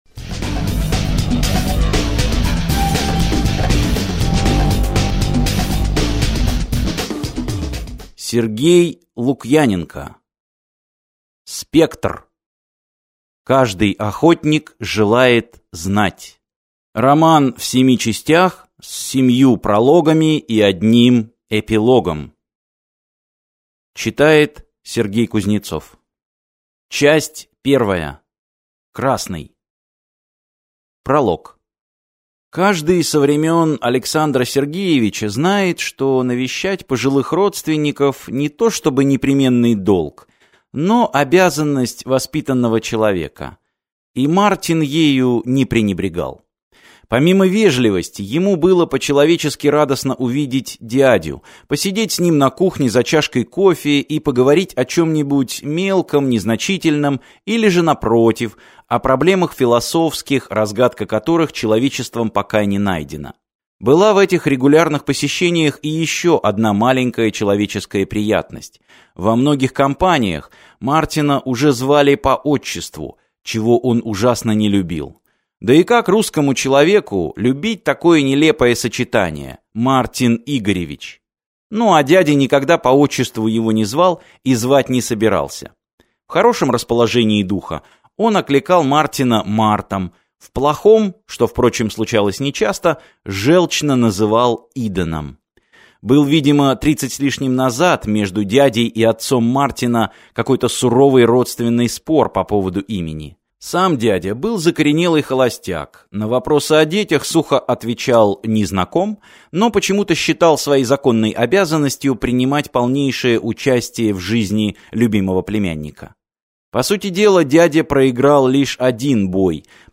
Аудиокнига Спектр - купить, скачать и слушать онлайн | КнигоПоиск